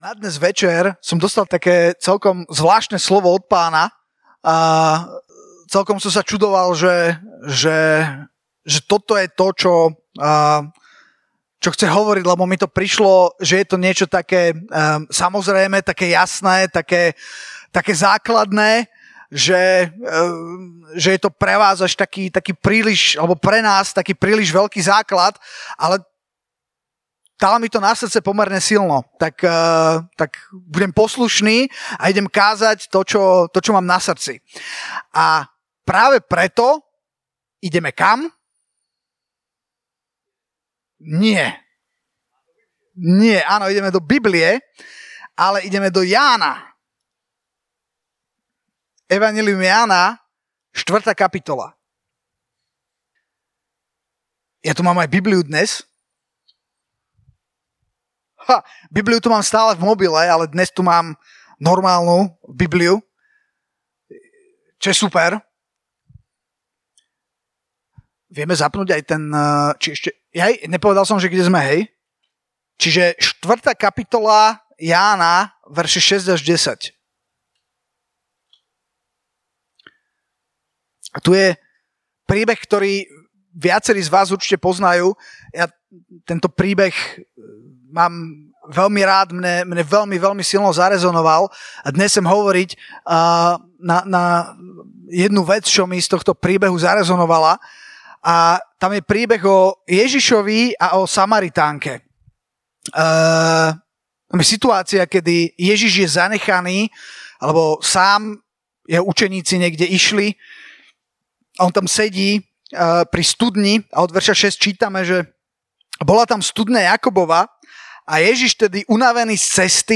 Nahrávky zo stretnutí mládeže Slova života Bratislava